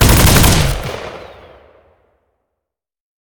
ogg / general / combat / abilities / MGUN / fire1.ogg
fire1.ogg